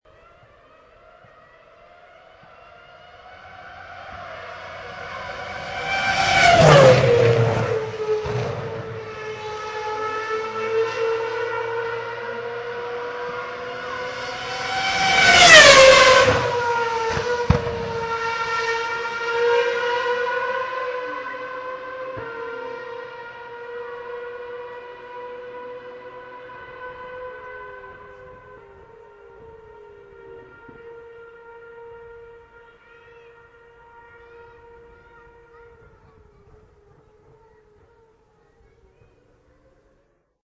Здесь собраны аудиозаписи, которые раскрывают весь потенциал стереозвука — от успокаивающих мелодий до динамичных эффектов.